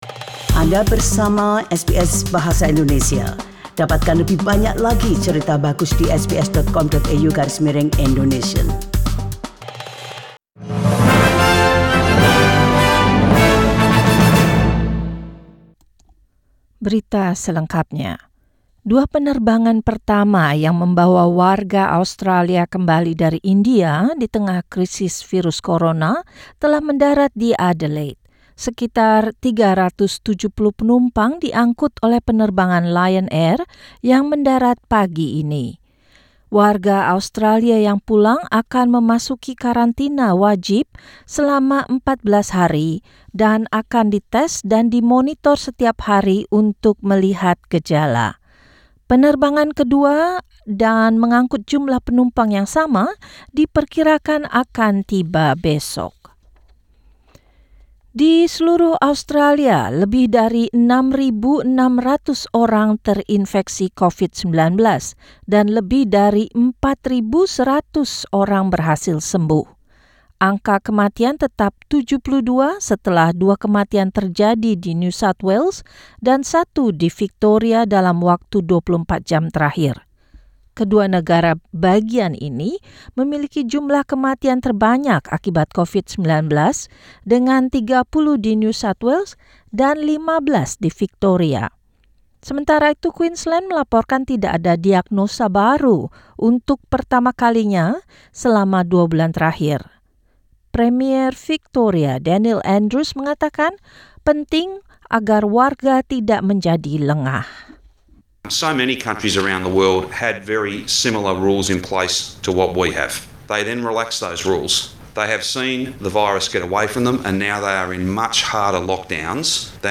SBS Radio News in Indonesian - 20 April 2020